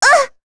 Rehartna-Vox_Damage_01.wav